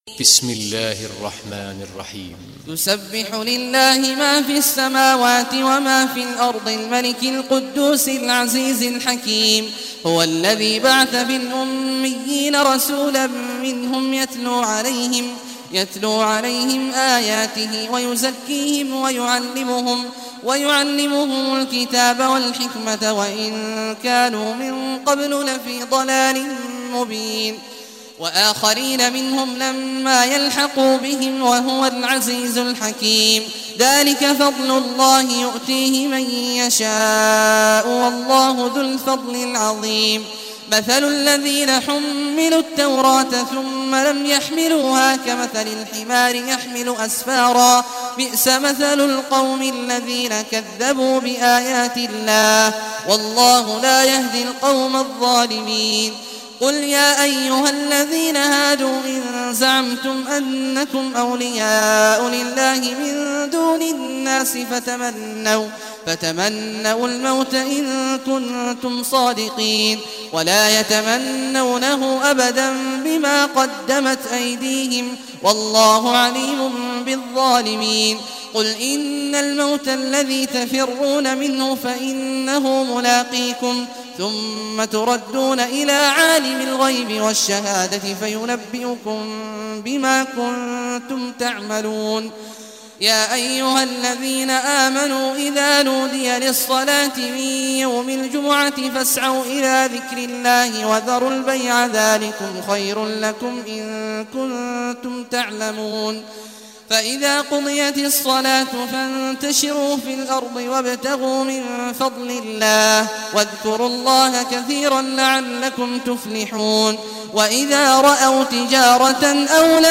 Surah Jumah Recitation by Sheikh Awad Juhany
Surah Jumah, listen or play online mp3 tilawat / recitation in Arabic in the beautiful voice of Sheikh Abdullah Awad al Juhany.